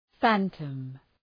Προφορά
{‘fæntəm}